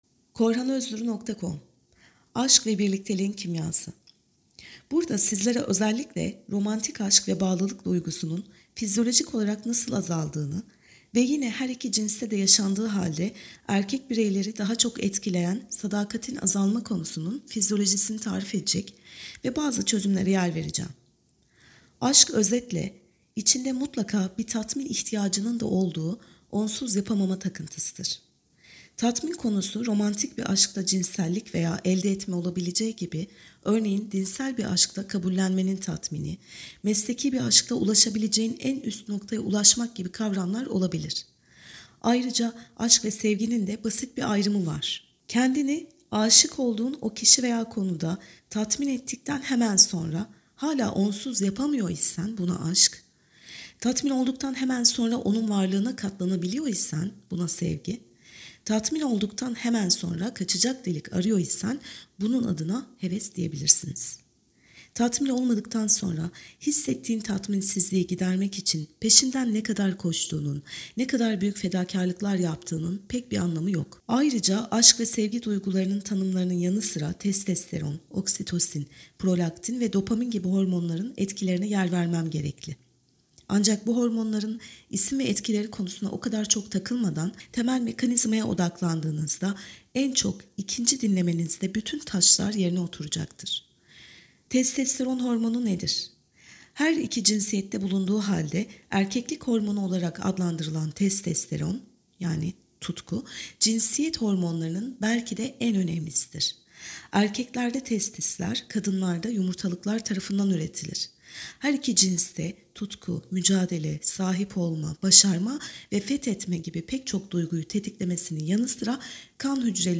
AskveBirlikteginKimyasiSesliOkuma.mp3